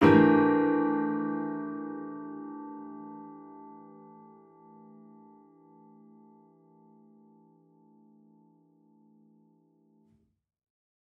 Index of /musicradar/gangster-sting-samples/Chord Hits/Piano
GS_PiChrd-Emin7+9.wav